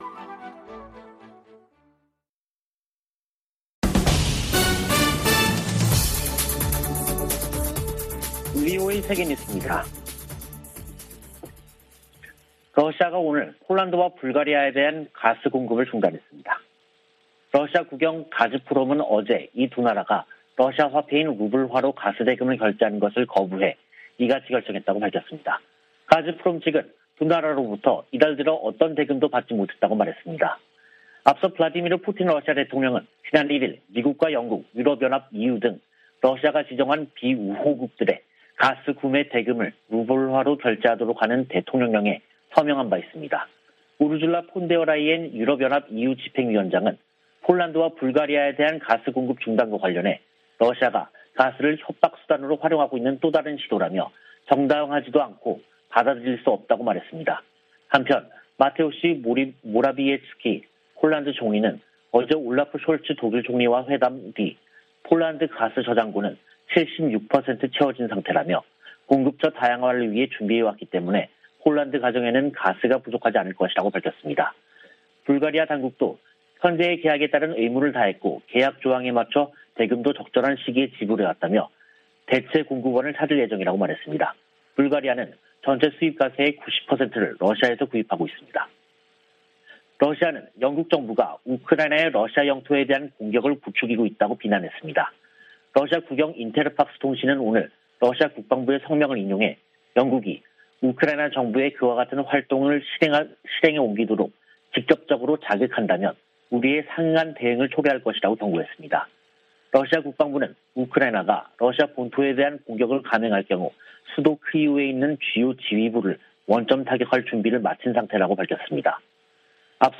VOA 한국어 간판 뉴스 프로그램 '뉴스 투데이', 2022년 4월 27일 3부 방송입니다. 미 국무부는 ‘핵무력’을 언급한 김정은 북한 국무위원장의 25일 연설에 대해 북한이 국제 평화와 안보에 위협이 되고 있다고 지적했습니다.